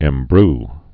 (ĕm-br)